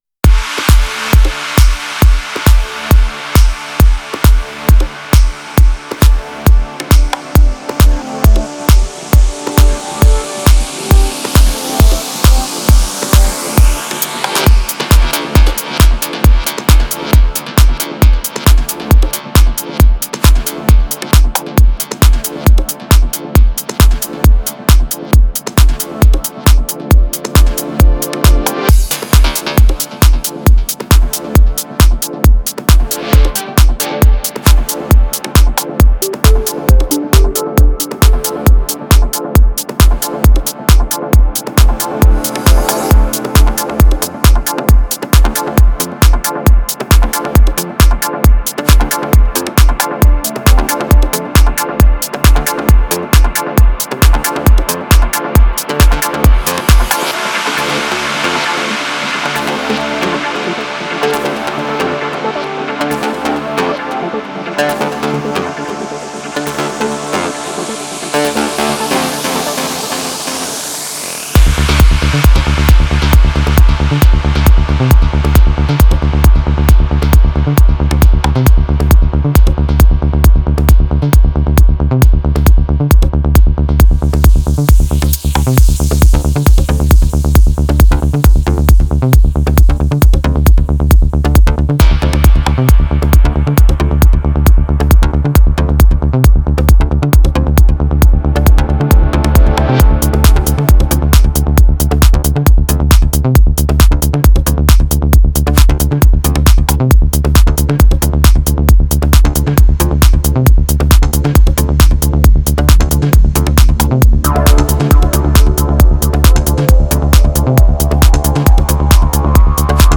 Файл в обменнике2 Myзыкa->Psy-trance, Full-on
Style: Psychedelic / Progressive